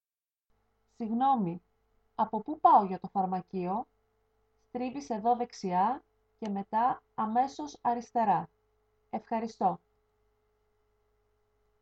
Dialog B: